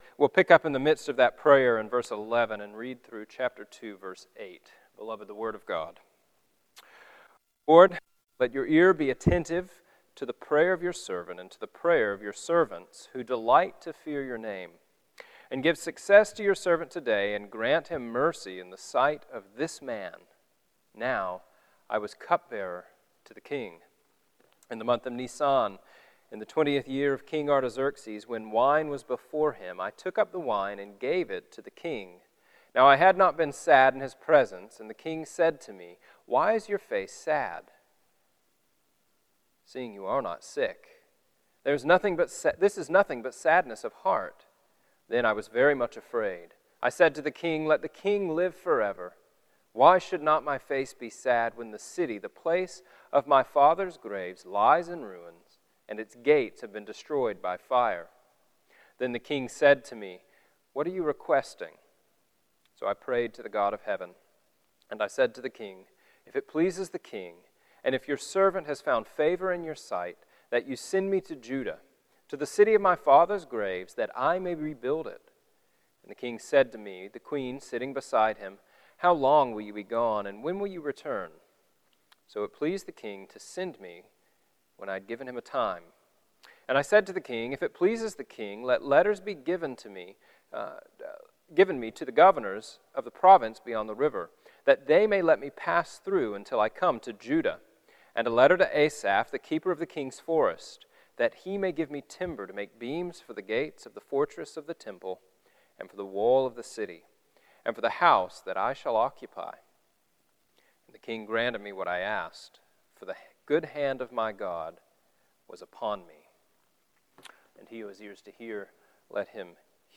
Bible Text: Nehemiah 2:1-8 | Preacher